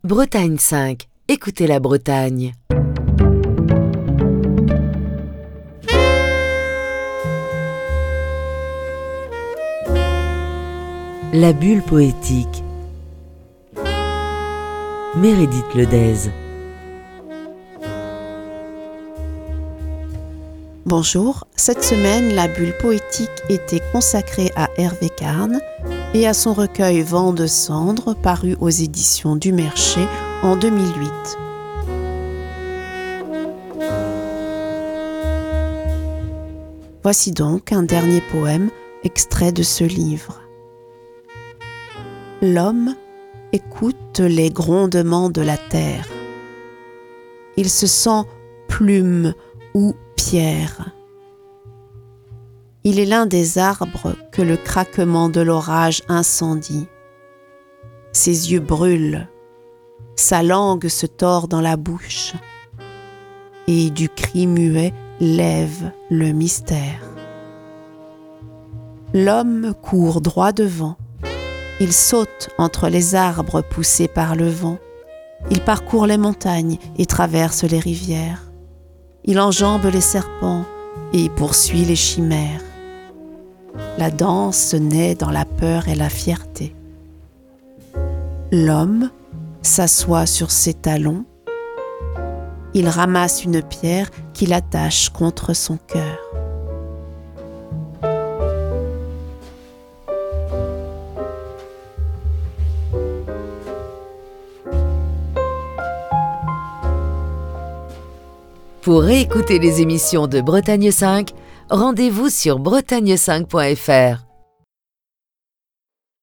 lecture de textes